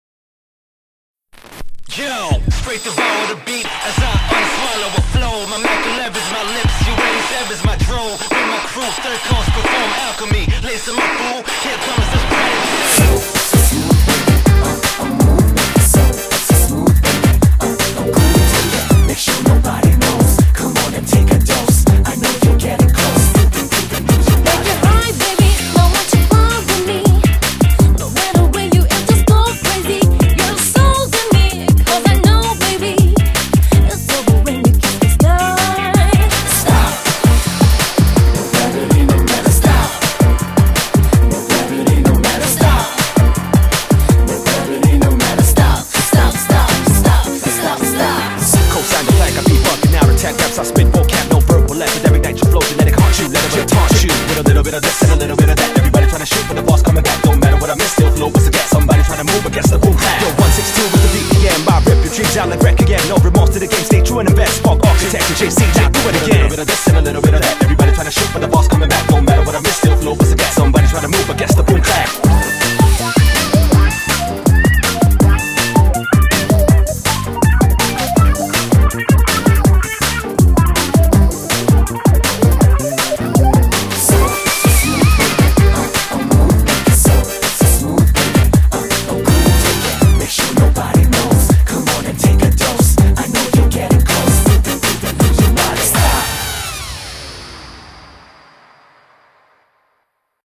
BPM90-162
Audio QualityPerfect (High Quality)
A jazzy hip-hop song